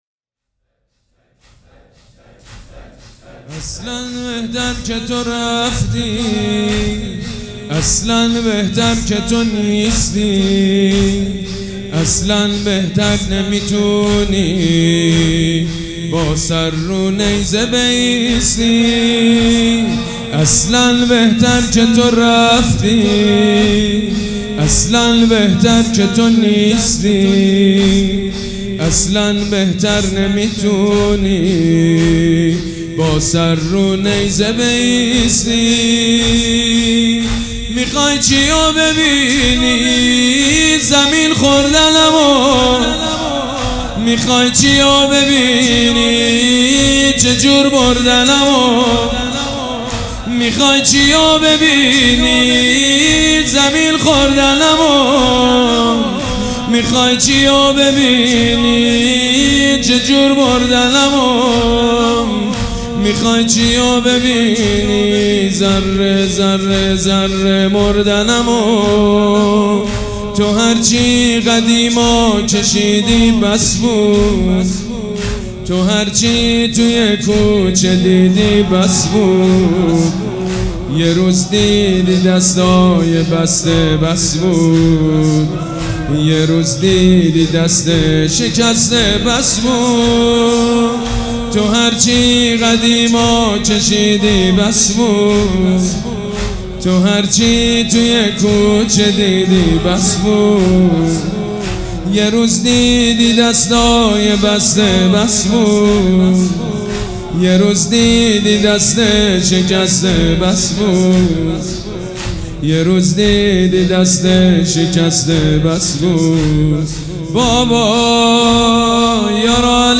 مداح
حاج سید مجید بنی فاطمه
مراسم عزاداری شب سوم